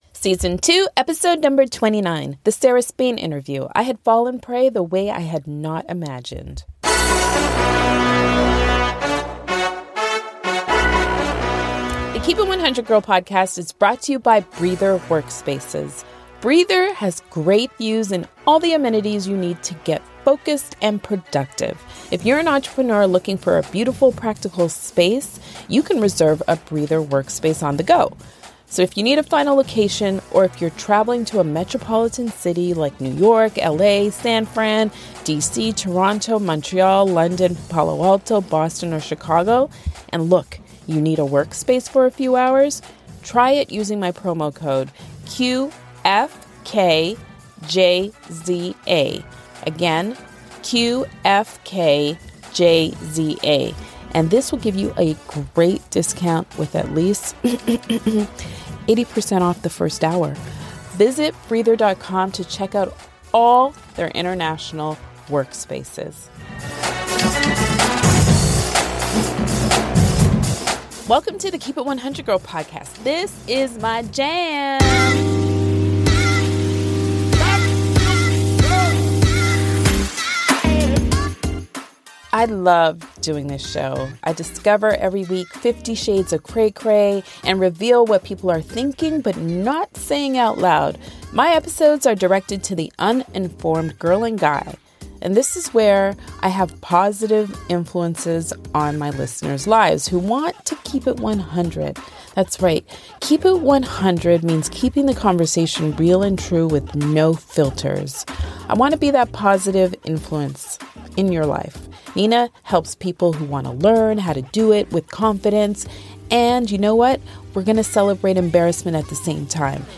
S2:EP29 - The Sarah Spain Interview - I Had Fallen Prey the Way I Had Not Imagined